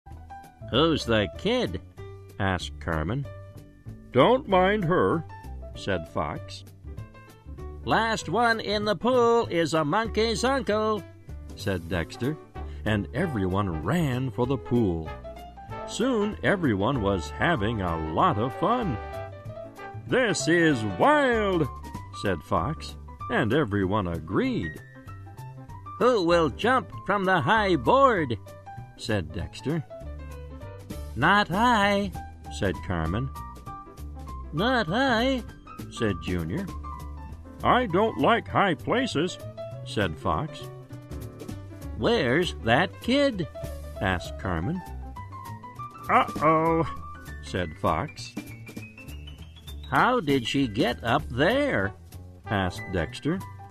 在线英语听力室小狐外传 第8期:游泳池的听力文件下载,《小狐外传》是双语有声读物下面的子栏目，非常适合英语学习爱好者进行细心品读。故事内容讲述了一个小男生在学校、家庭里的各种角色转换以及生活中的趣事。